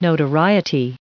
531_notoriety.ogg